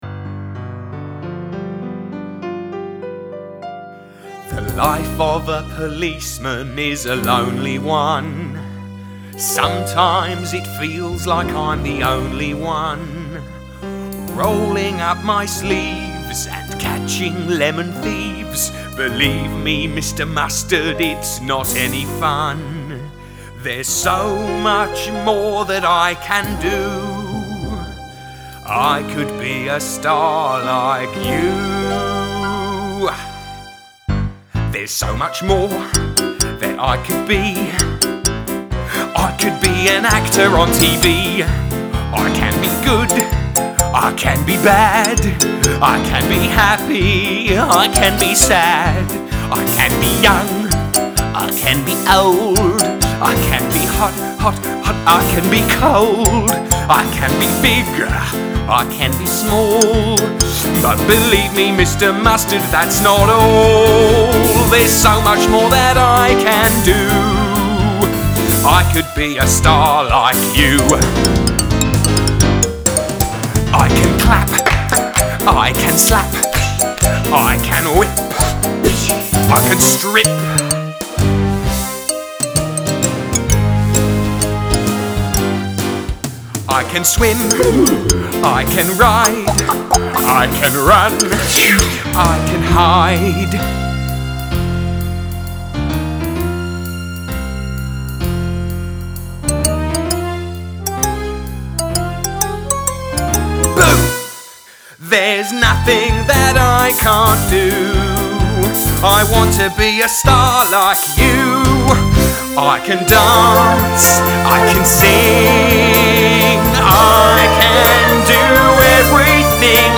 Join us to sing the funny song of the policeman Frank Peel